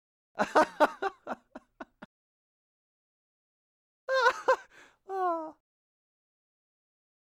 男人哈哈笑声音效免费音频素材下载